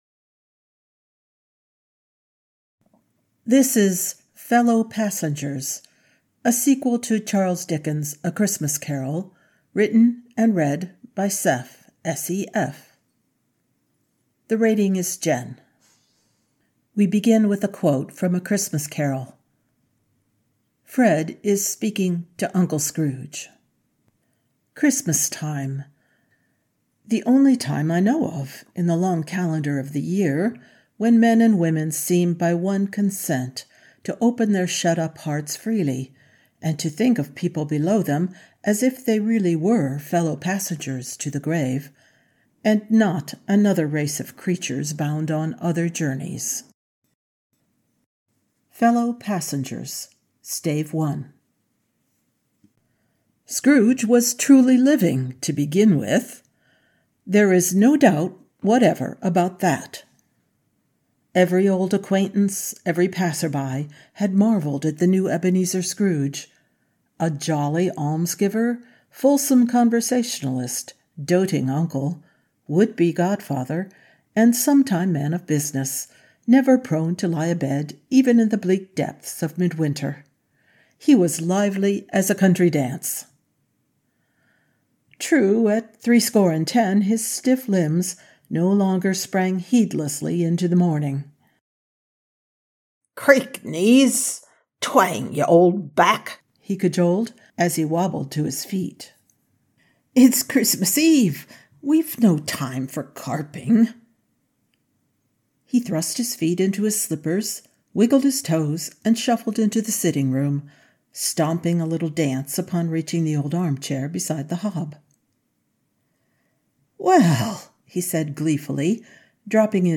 with music and effects download mp3: here (r-click or press, and 'save link') [25 MB, 00:31:32]